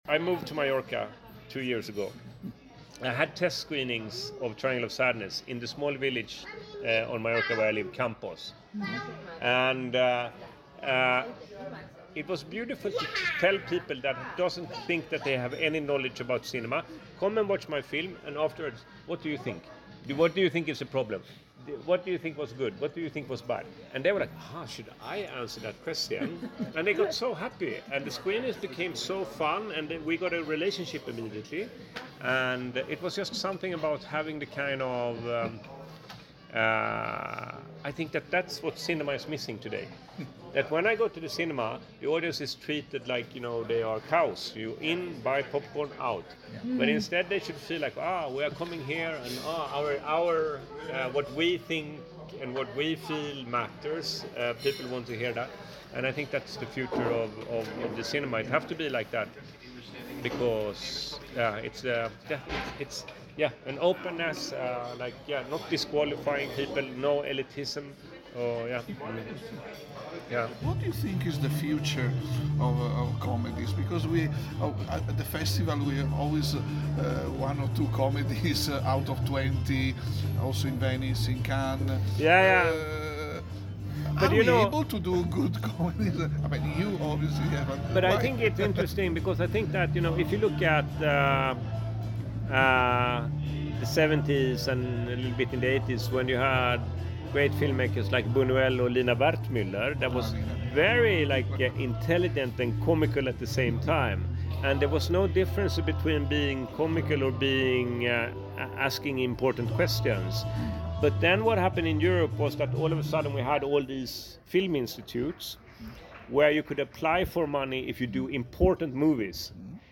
Interview
Maestia Svaneti Film Festival, Géorgie.